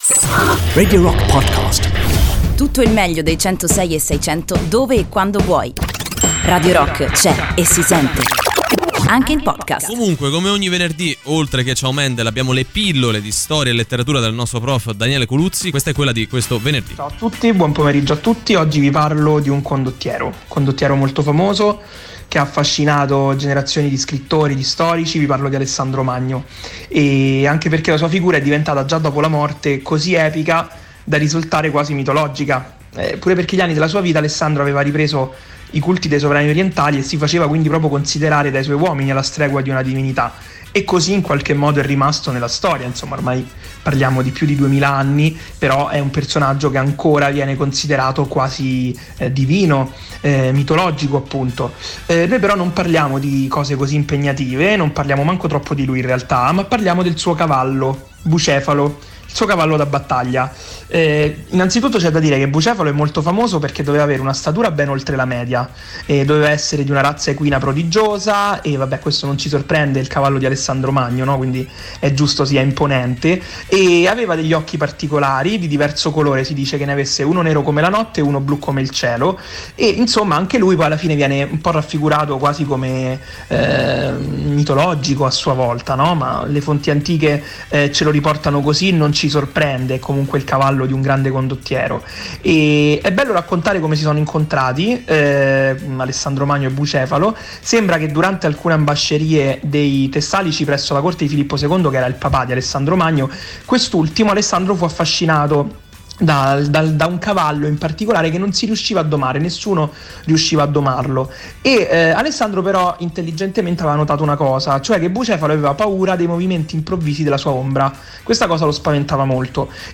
in diretta su Radio Rock